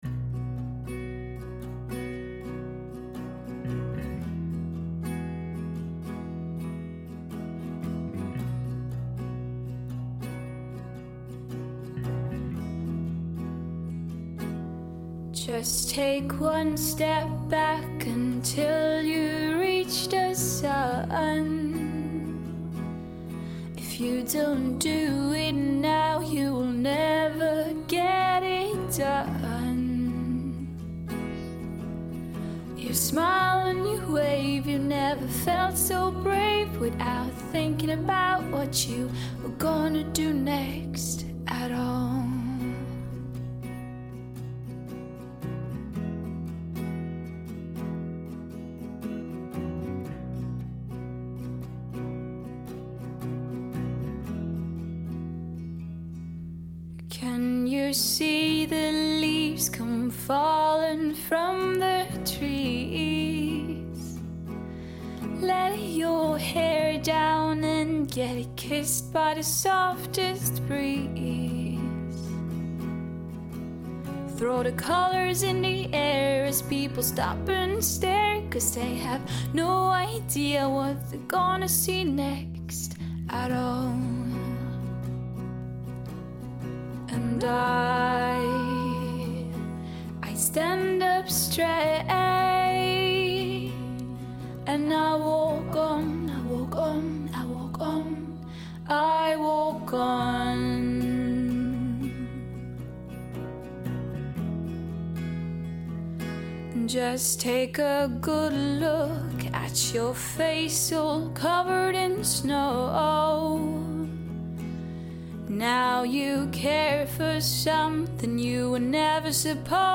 I had to use headphones which I know is sort of a "no no" but, I'd love to hear what you all think.
(as in mixed it again, there is no hip hop beat....LOL) I don't know who wrote or recorded it or who sang it.
The guitars were pretty hard to get to sound decent, but I did what I could.